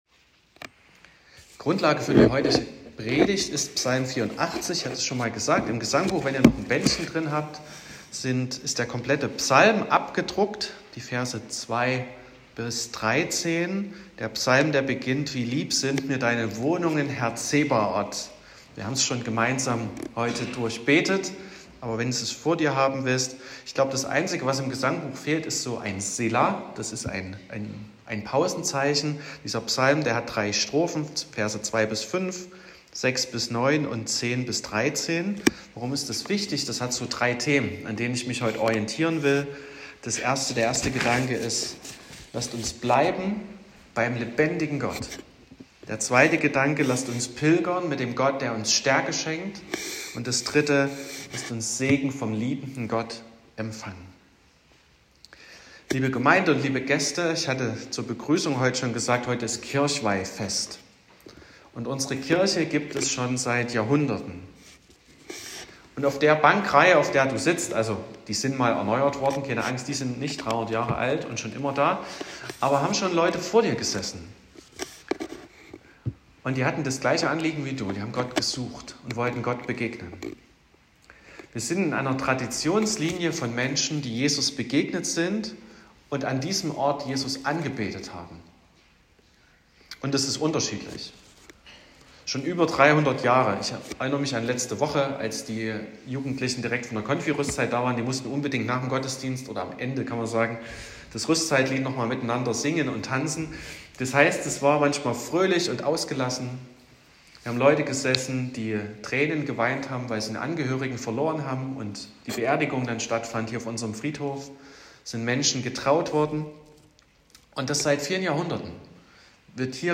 26.10.2025 – Gottesdienst zum Kirchweihfest
Predigt (Audio): 2025-10-26_Der_lebendige_Gott.m4a (7,1 MB)